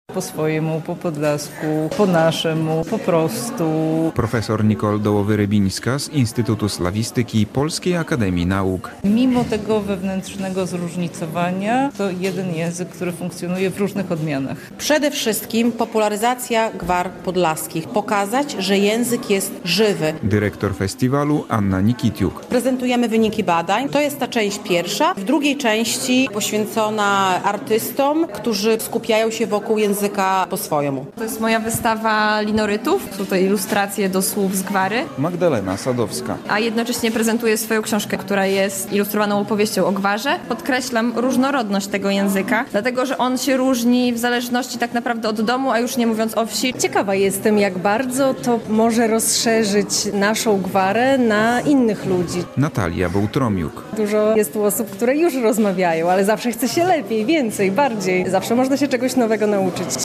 III Festiwal Gwar Pogranicza - relacja